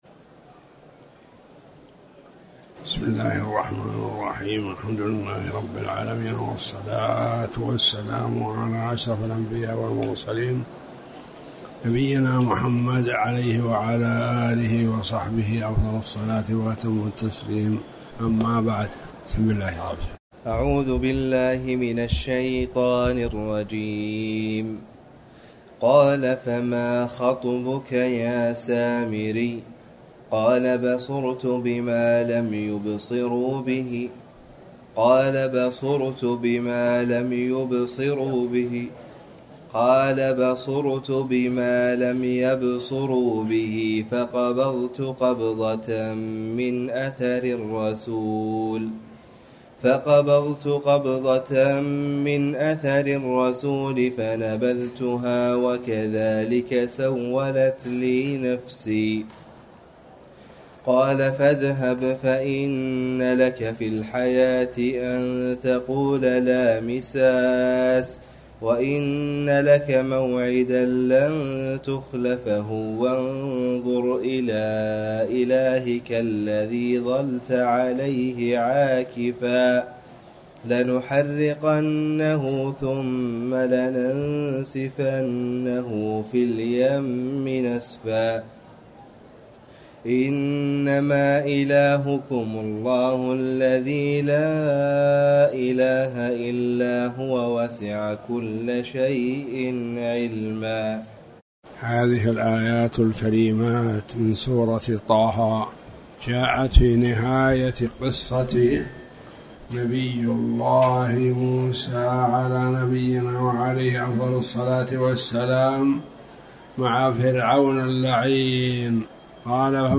تاريخ النشر ٢٧ ربيع الأول ١٤٤٠ هـ المكان: المسجد الحرام الشيخ